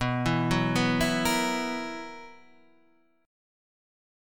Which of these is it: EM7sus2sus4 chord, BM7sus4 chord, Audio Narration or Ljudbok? BM7sus4 chord